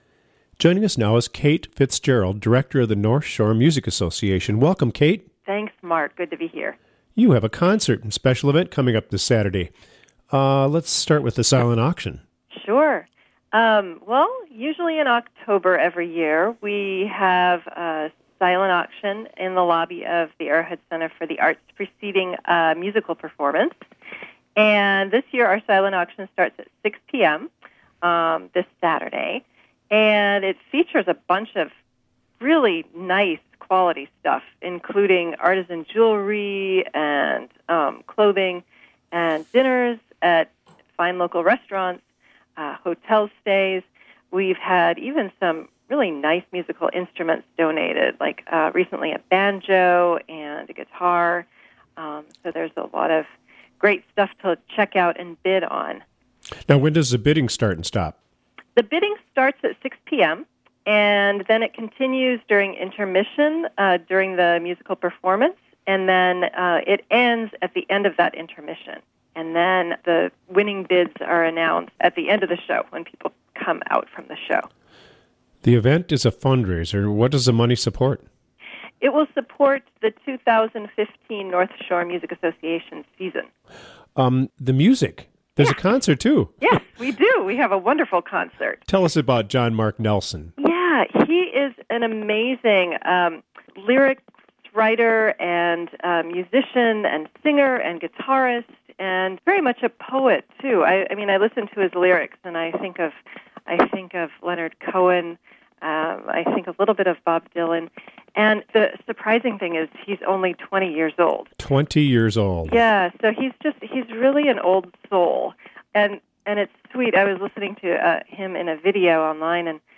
WTIP volunteer